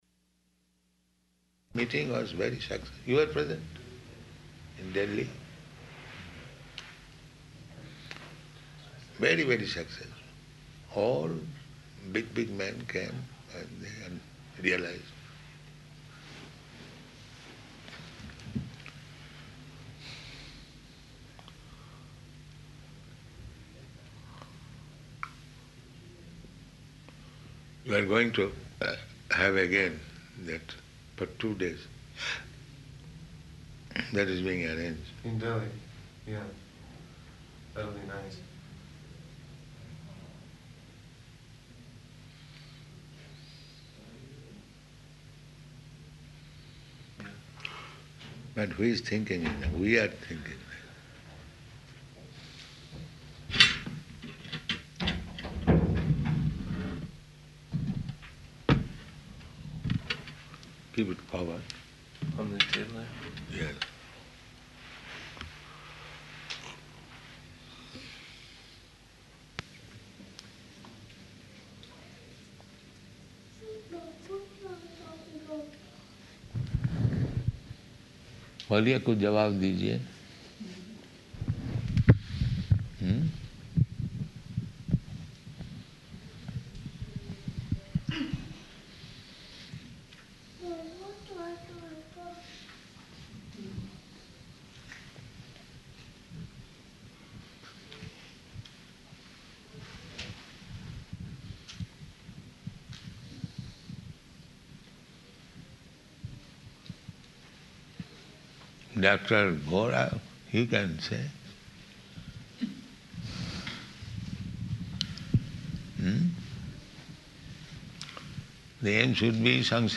Room Conversation with Indian Guests
Room Conversation with Indian Guests --:-- --:-- Type: Conversation Dated: March 13th 1975 Location: Tehran Audio file: 750313R2.TEH.mp3 Prabhupāda: ...meeting was very successful.